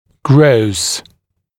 [grəus][гроус]большой, явный, бросающийся в глаза; макроскопический